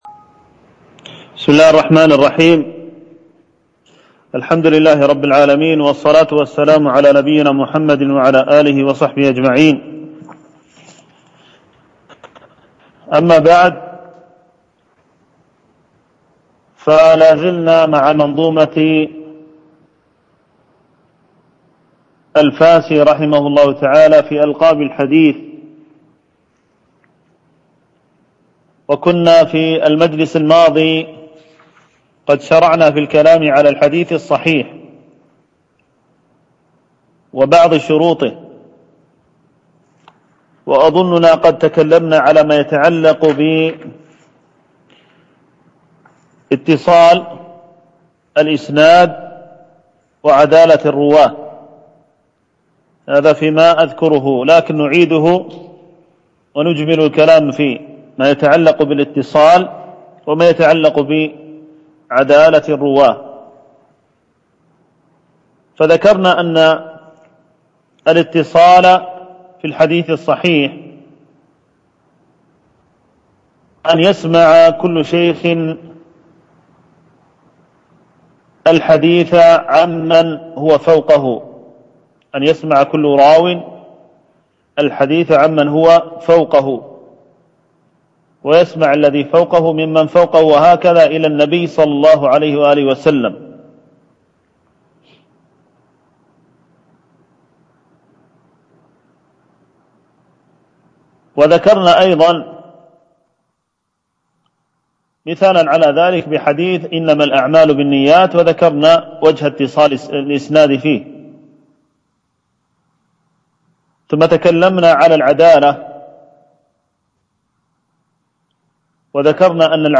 شرح طرفة الطرف في مصطلح من سلف - الدرس الرابع